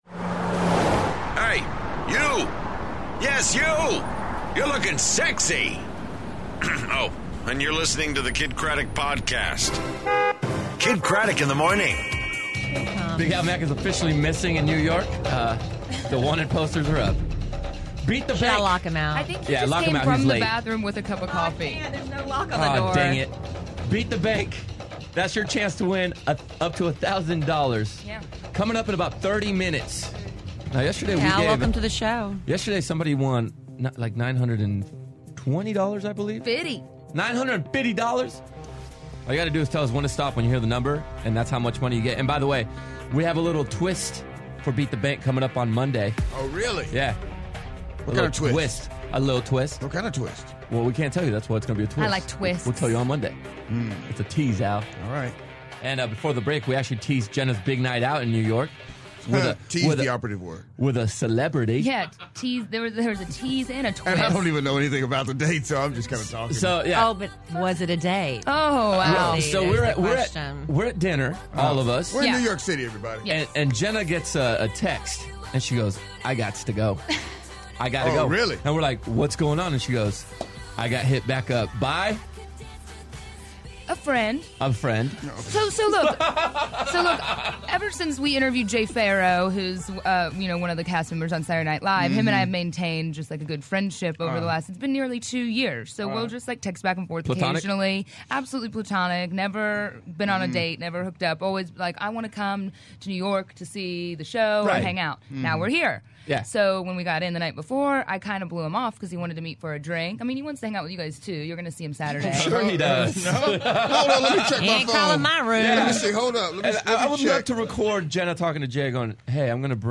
LIVE FROM NEW YORK!